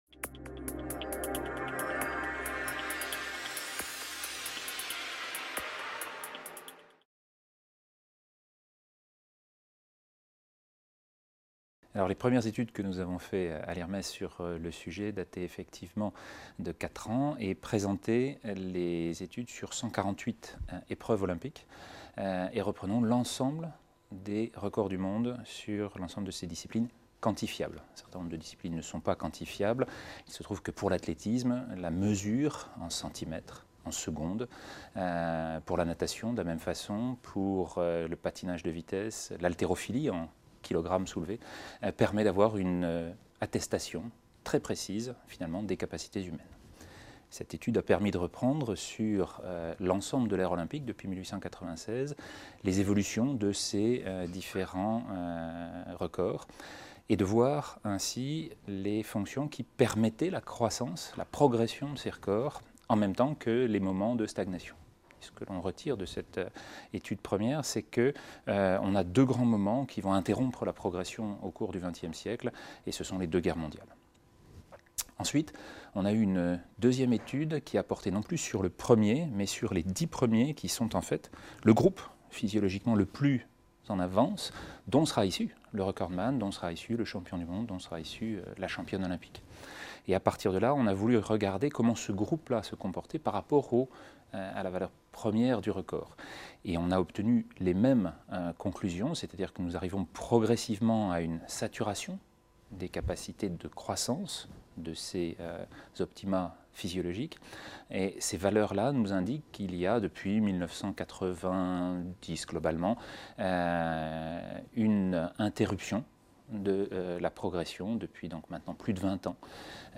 Que révèle l'étude de l'évolution des records ? (Interview